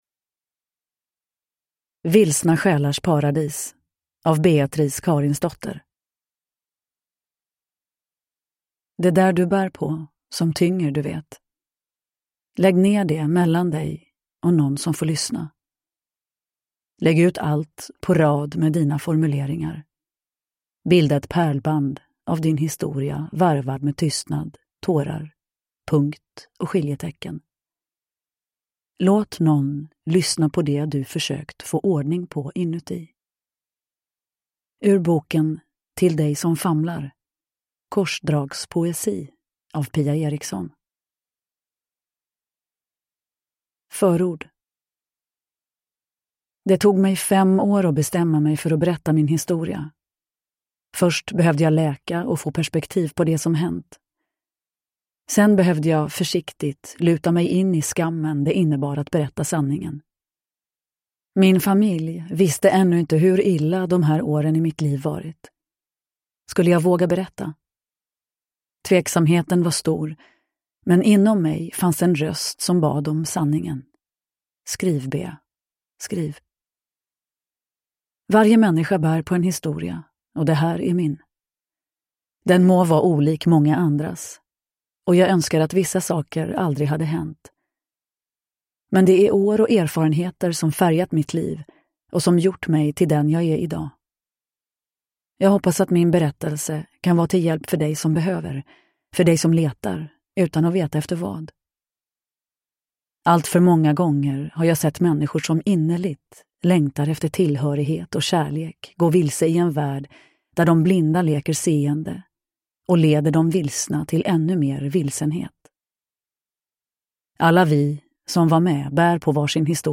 Uppläsare: Nina Zanjani
• Ljudbok